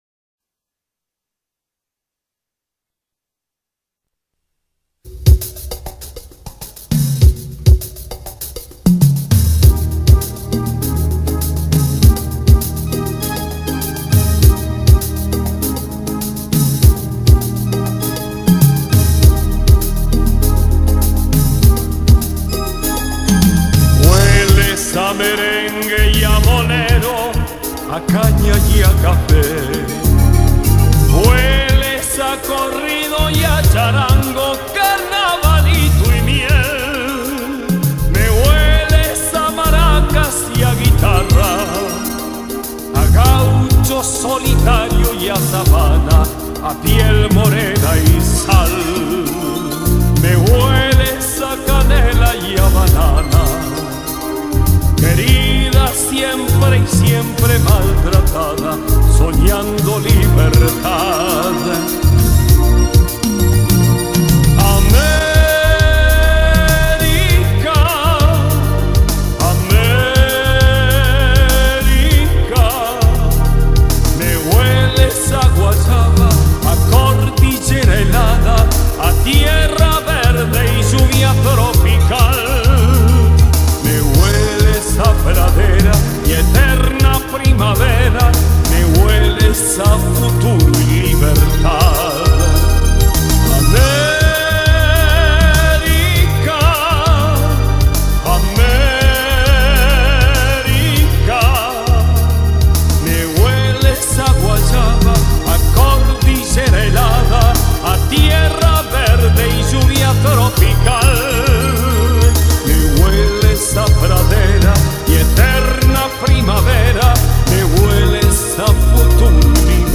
Baladas